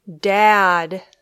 e sound æ sound